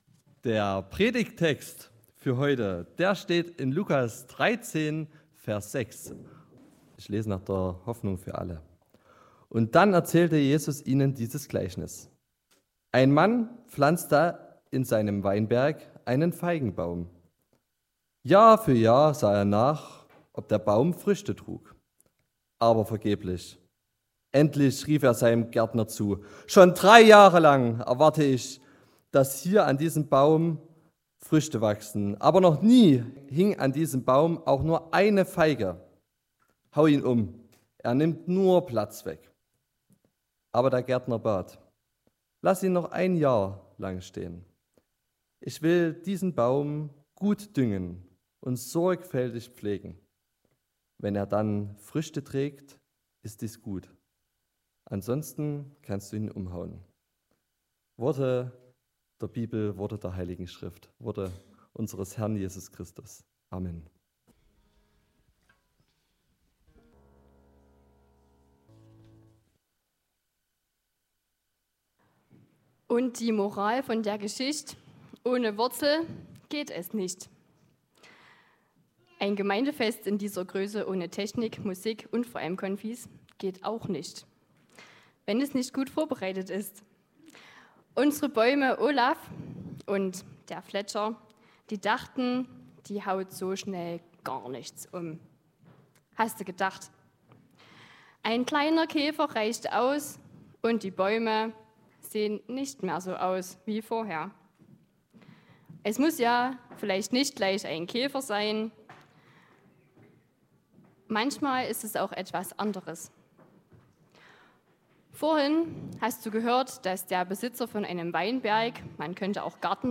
Zum Gemeindefest geht es heute um das Motto "starke Wurzeln schlagen". Ein Baum benötigt seine Wurzeln, um zu wachsen, einen starken Stamm und viele Äste zu bekommen.
6-9 Gottesdienstart: Gemeinsamer Gottesdienst Zum Gemeindefest geht es heute um das Motto "starke Wurzeln schlagen".